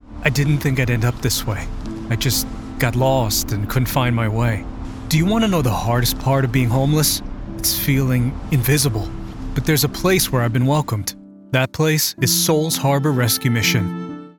Male
Natural Speak
"Real-Person" Promo Spot